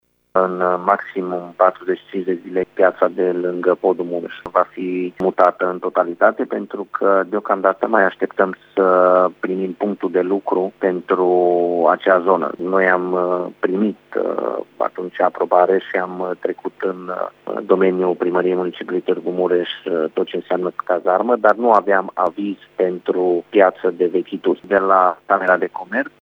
Viceprimarul Tîrgu-Mureșului Claudiu Maior: